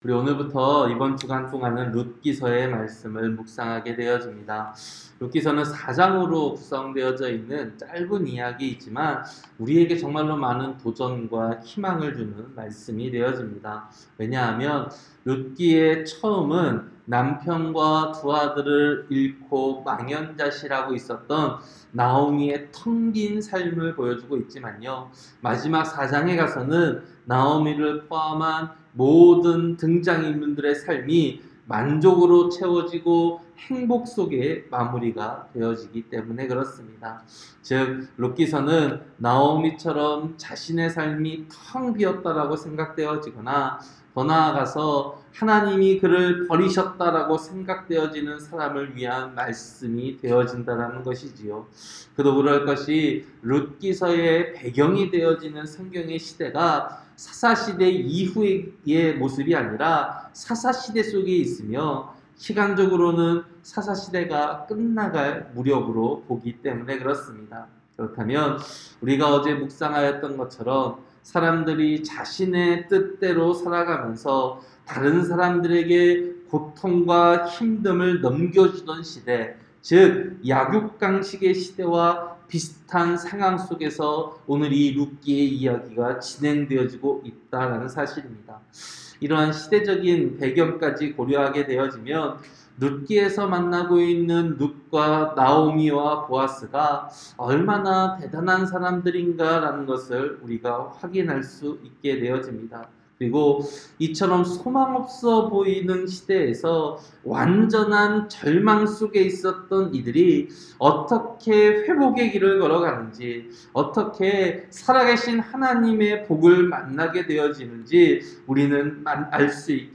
새벽설교-룻기 1장